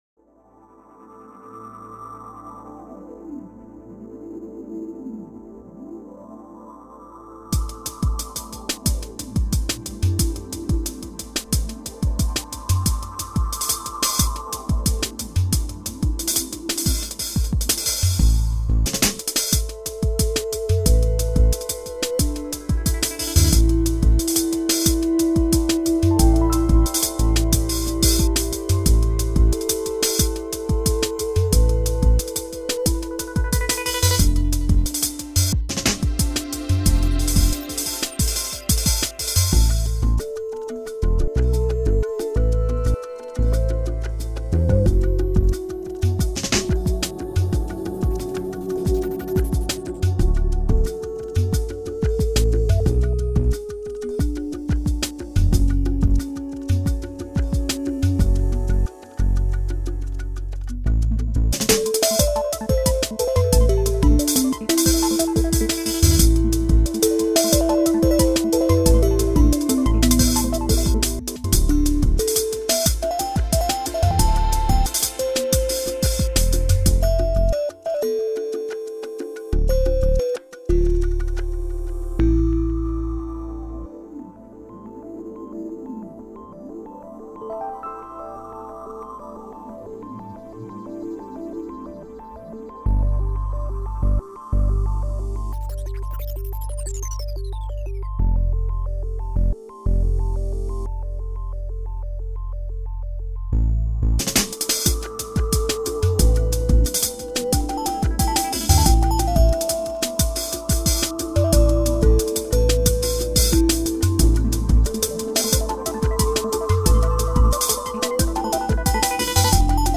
Download - Electronic City Atmosphere